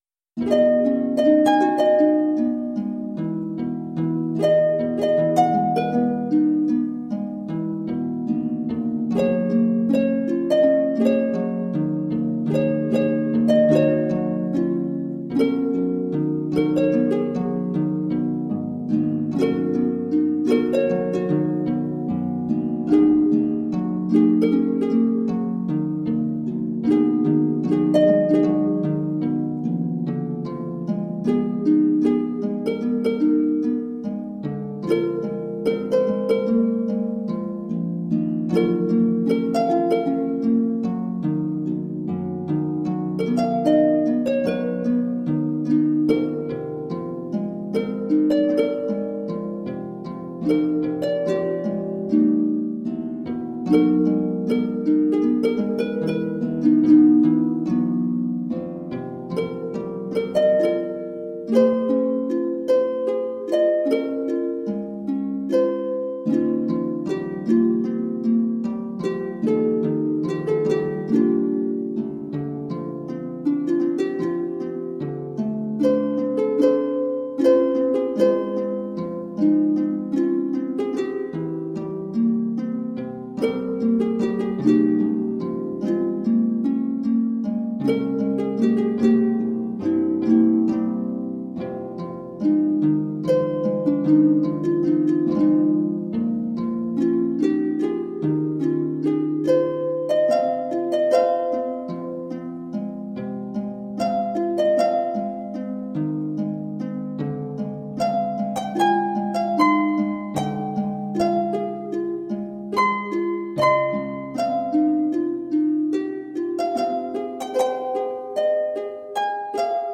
Magical, heart inspiring music.